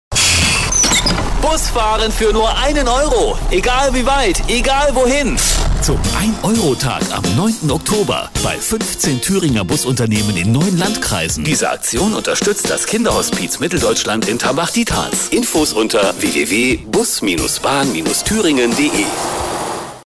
Radiospot Zurück